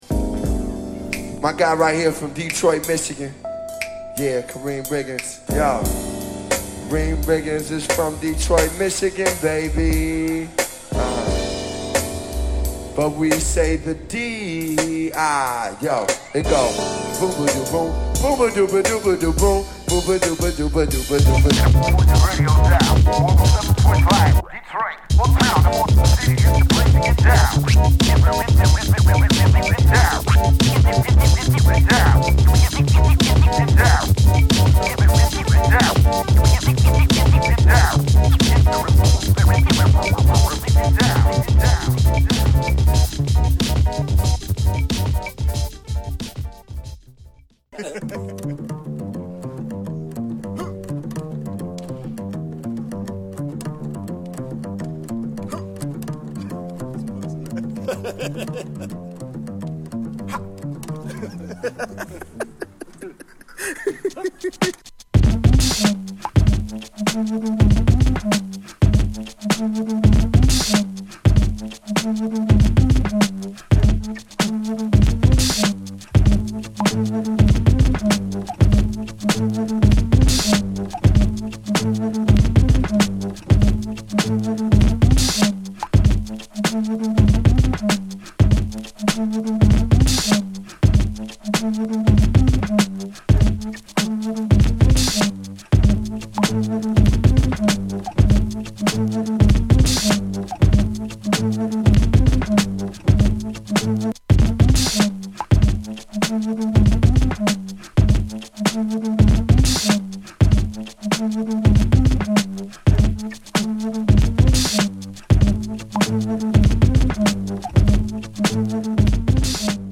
Sampling Beats